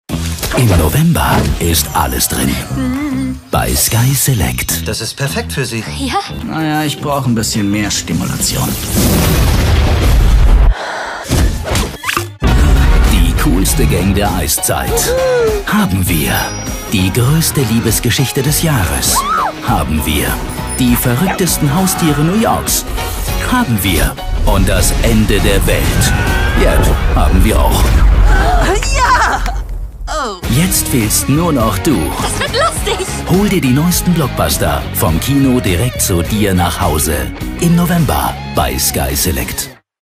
Sprechprobe: eLearning (Muttersprache):
Sky Select laut Trailaudio.mp3